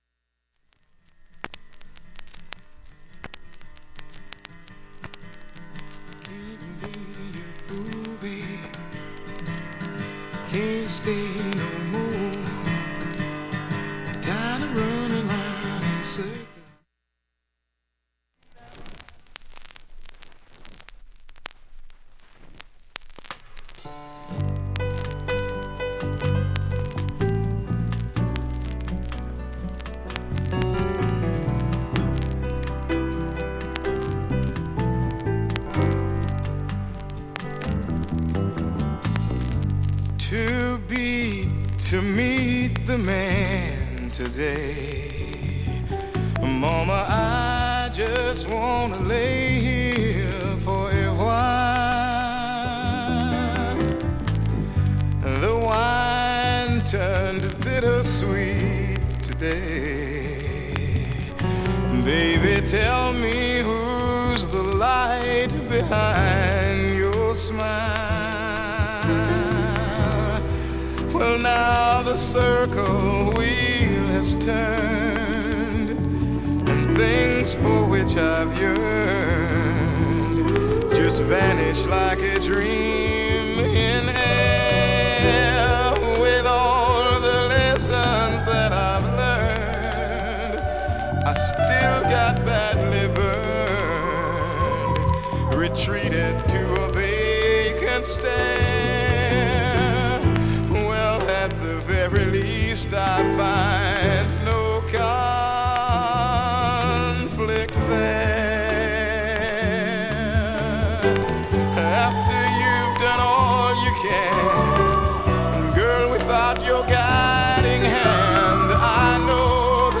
大半普通に聴けると思います。
バックチリなどはほとんどありません。 B-3などは静かな部でもかすかです。